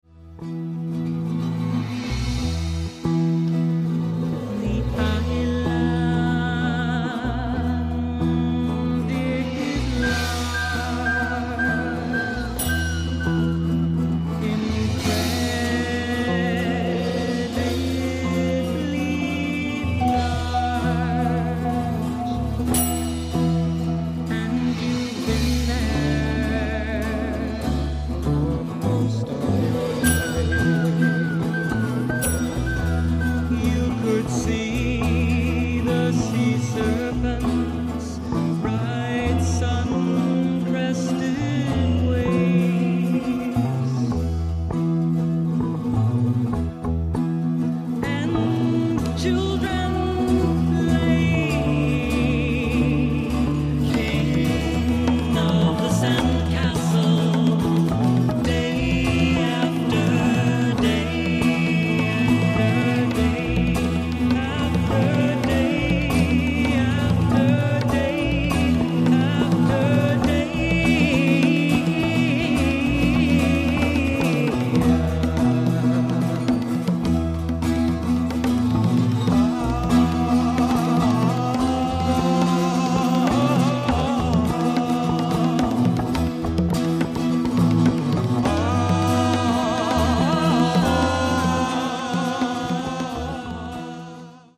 Canadian Psych Folk1969
Vocals & Twelve String Guitar
Acoustic Bass
Hand Drums
Eerie & introspective.